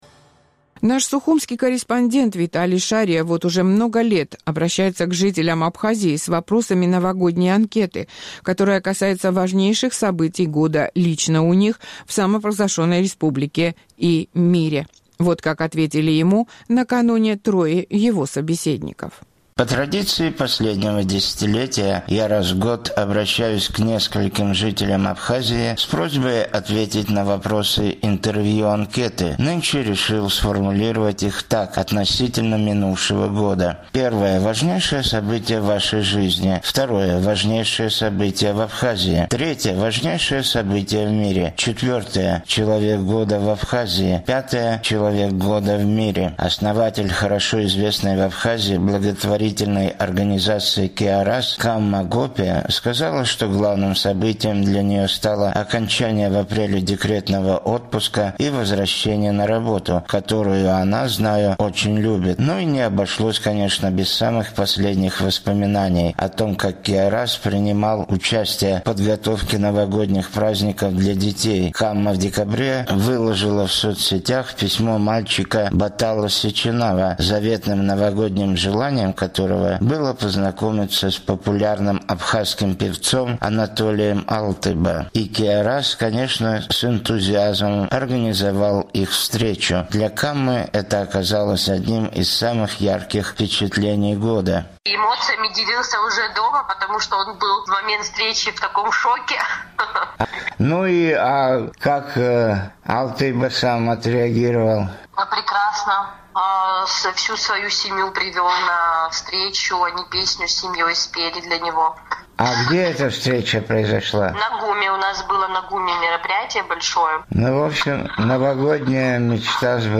2022: интервью-анкета в Абхазии